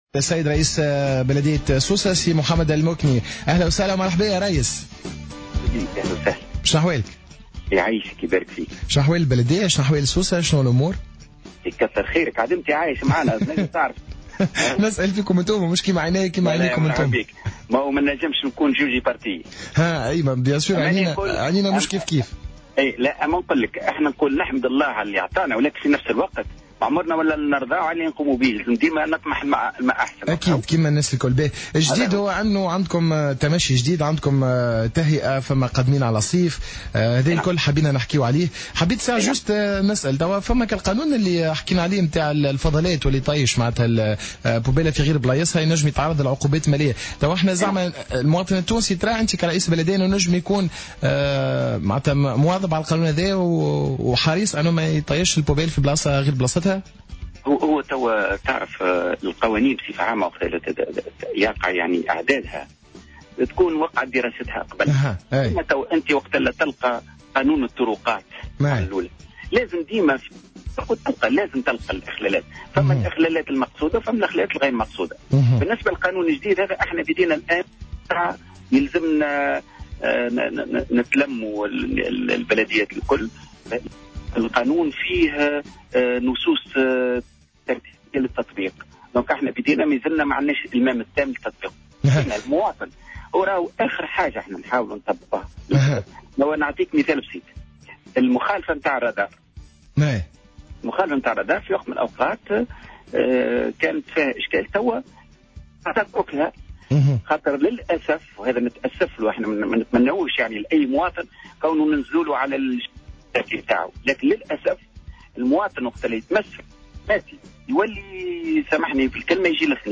تحدث رئيس النيابة الخصوصية ببلدية سوسة، محمد المكني، في حوار مع الجوهرة أف أم اليوم الخميس خلال حصة Happy Days، عن استعدادت البلدية للموسم الصيفي.